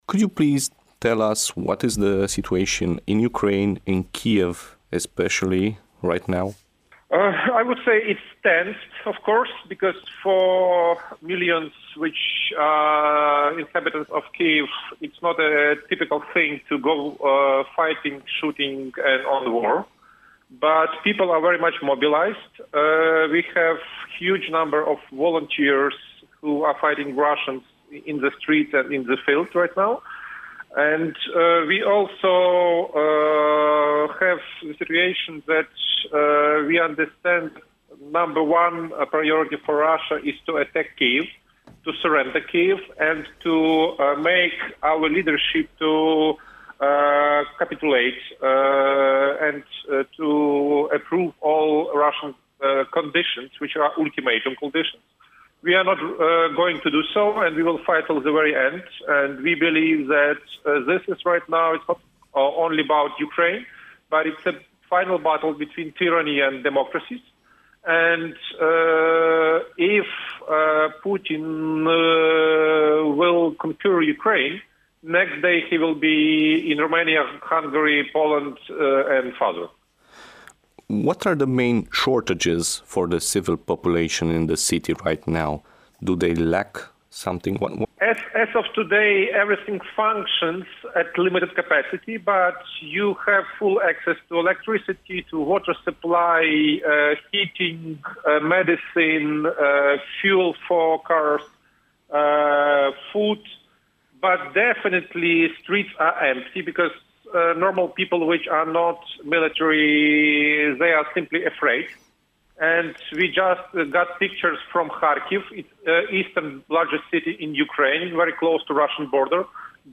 Interview in ENGLISH (AUDIO):